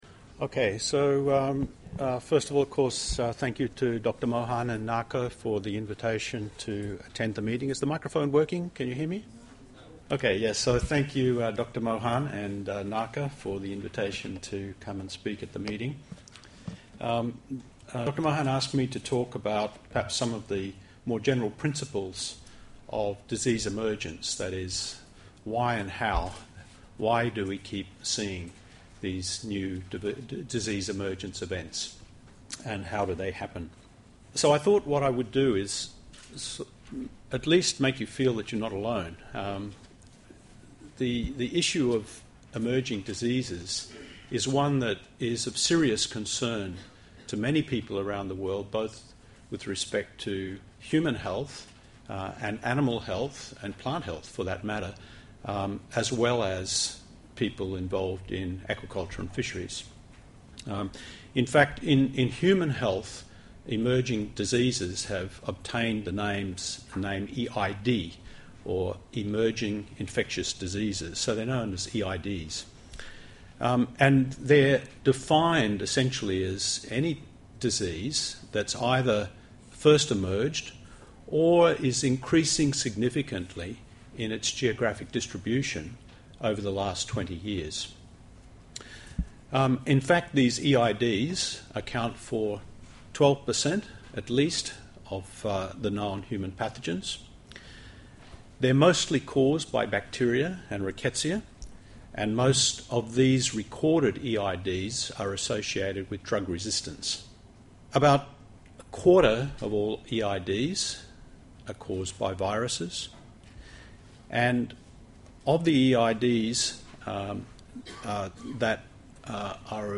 Presentation on mechanisms for the emergence of new diseases.